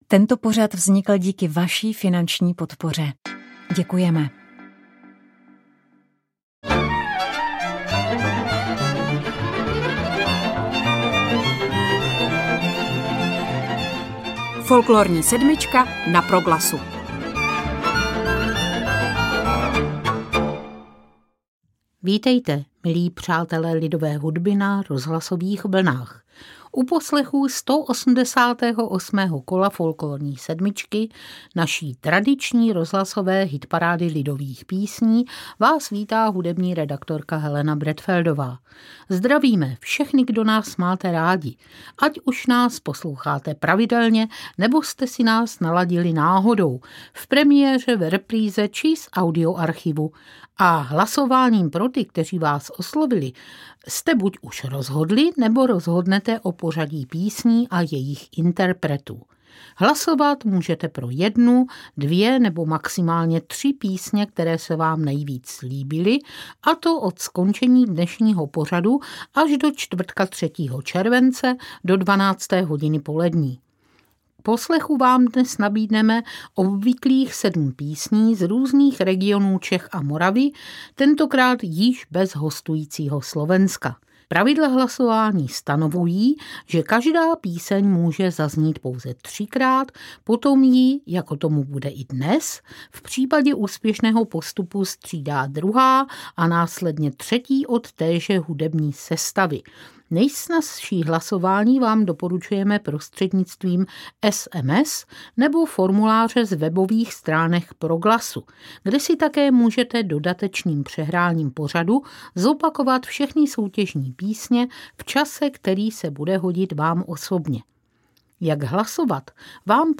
sugestivní milostnou táhlicí
cimbálová muzika
žertovná písnička
Vystřídá ji sada vojenských písní z Uherskobrodska A v tem Brodě